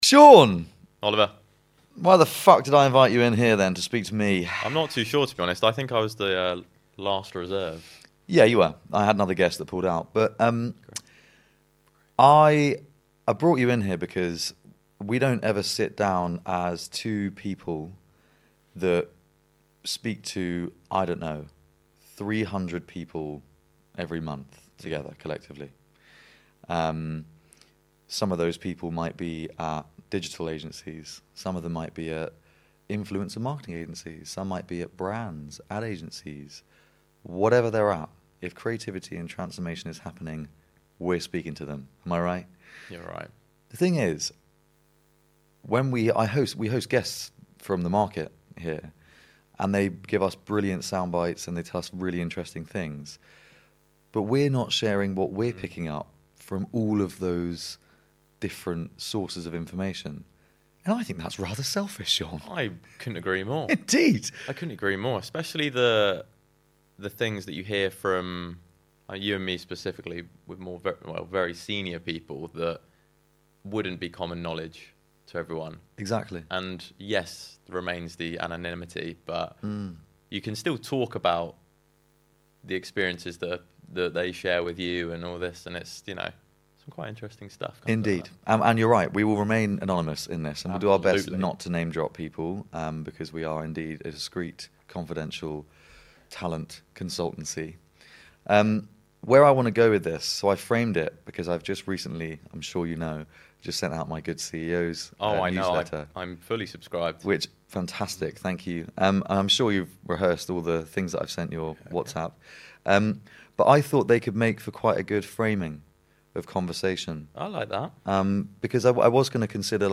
Today is a Boys only podcast